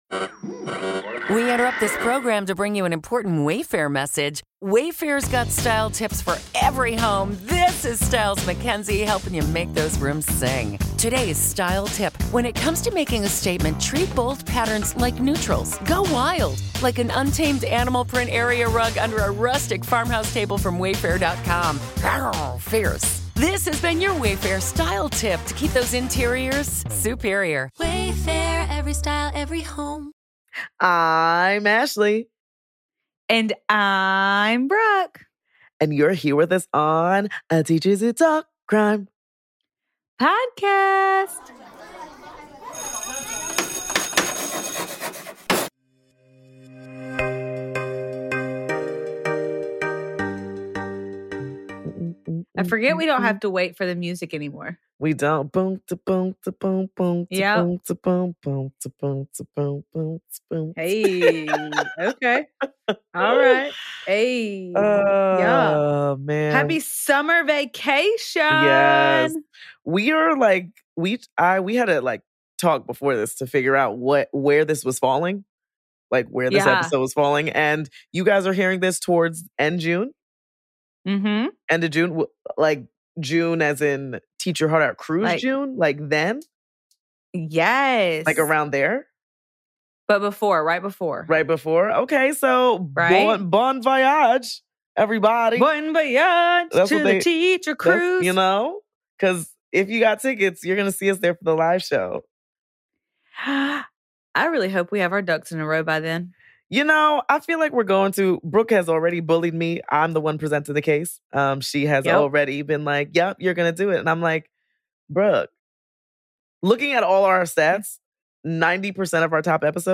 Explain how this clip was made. As always we are chatting in the beginning and we hope you listen along and chat back with us.